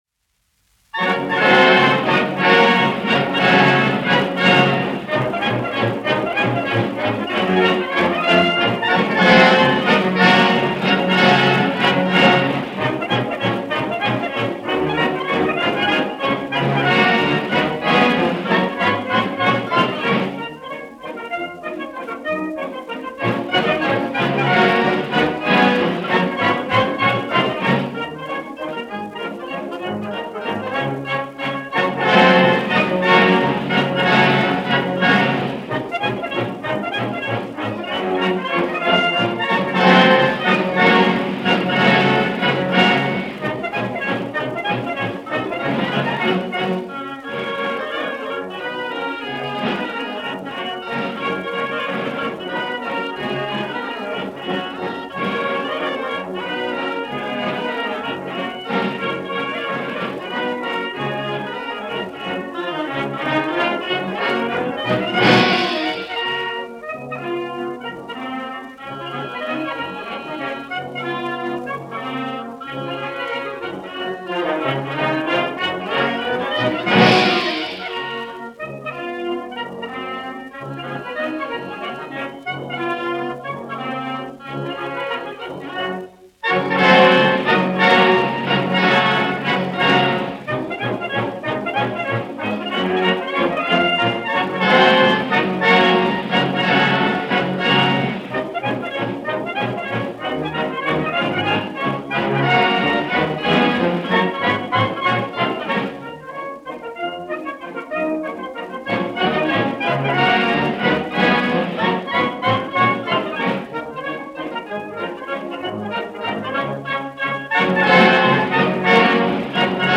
1 skpl. : analogs, 78 apgr/min, mono ; 25 cm
Pūtēju orķestra mūzika
Latvijas vēsturiskie šellaka skaņuplašu ieraksti (Kolekcija)